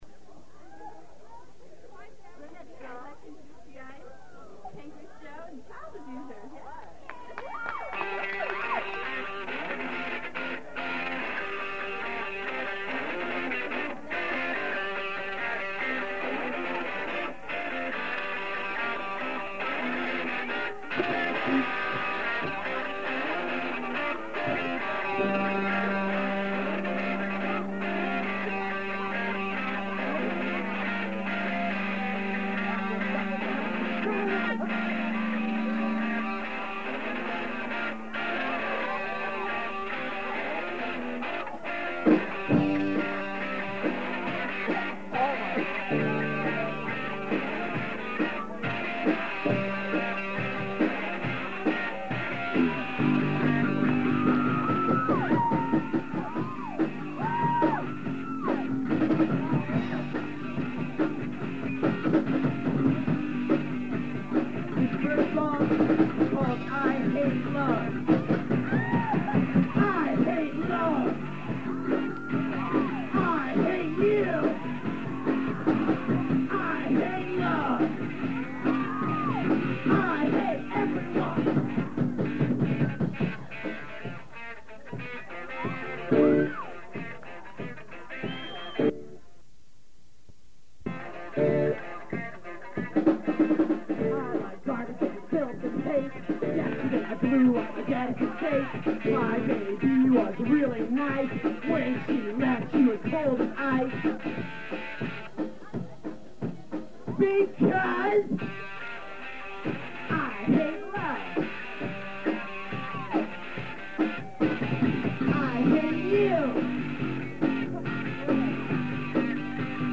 Next came PJ & CA, who played a humorous and rough 30 minute set of originals and covers, including the Canker Sore penned "Sadistic Drugs" and the Maggot/Pancreas anthem "Legalize Suicide".
PJ & CA's set: (* denotes cover)